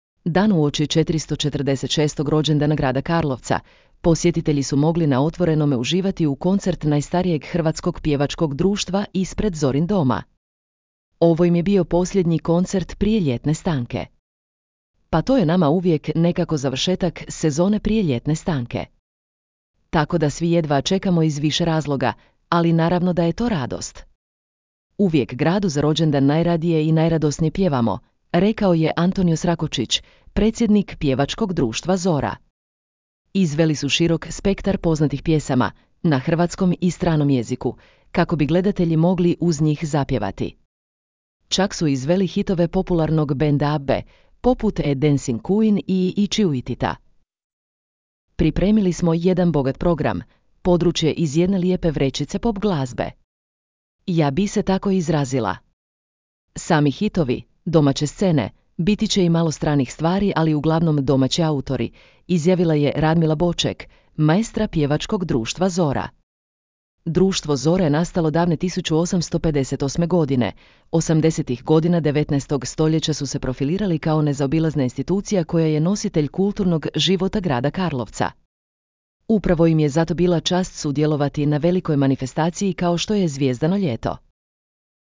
Dan uoči 446. rođendana grada Karlovca, posjetitelji su mogli na otvorenome uživati u koncert najstarijeg hrvatskog pjevačkog društva ispred Zorin doma. Ovo im je bio posljednji koncert prije ljetne stanke.
Izveli su širok spektar poznatih pjesama, na hrvatskom i stranom jeziku, kako bi gledatelji mogli uz njih zapjevati.